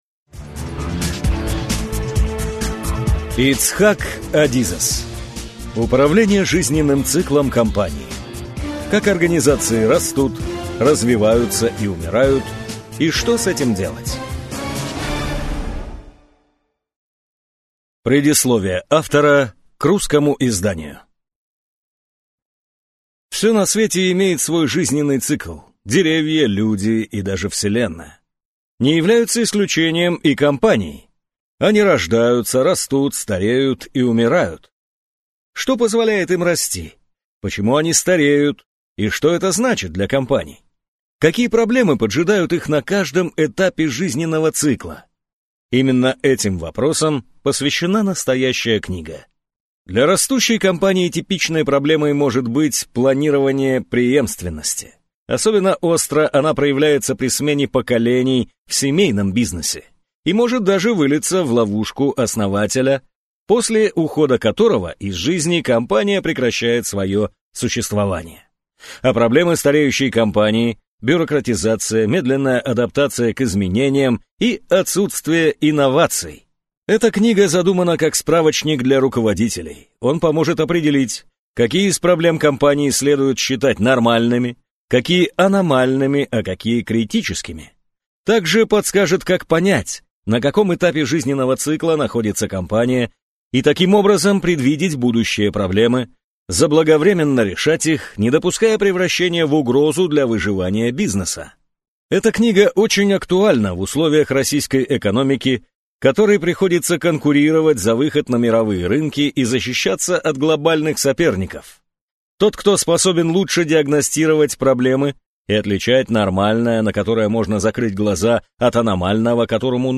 Аудиокнига Управление жизненным циклом компании. Как организации растут, развиваются и умирают и что с этим делать | Библиотека аудиокниг